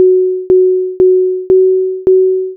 メロディ